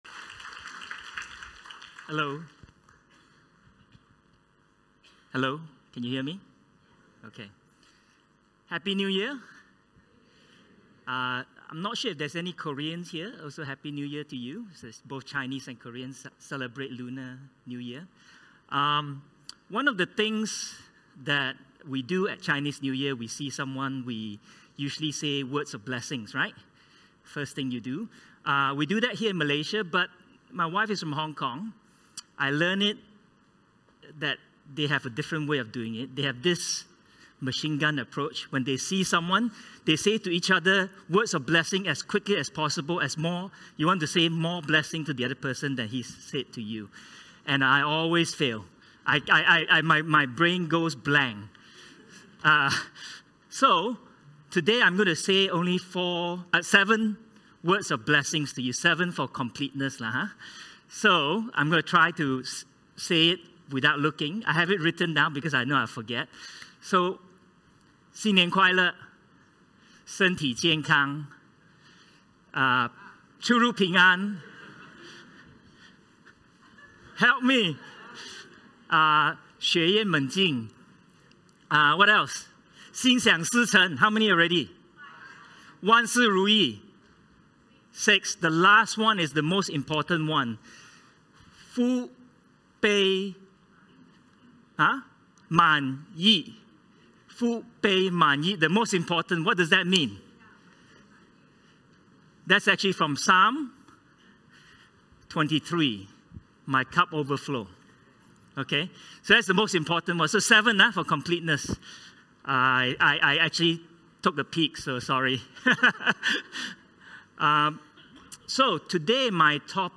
This sermon, titled “Royal Priesthood,” explores the concept of every believer’s identity in Christ as a royal priest, drawing from 1 Peter 2:9. It emphasizes that God has chosen all believers to be priests, not just a select few.